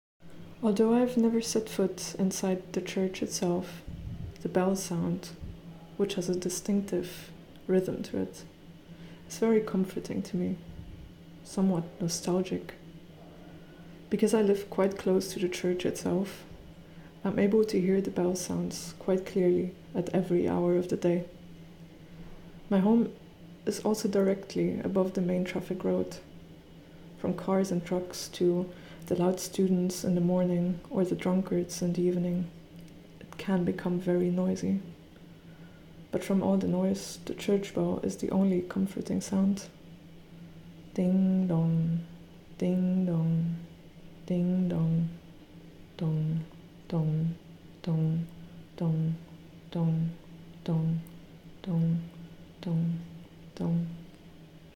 FieldRecordingMemory.mp3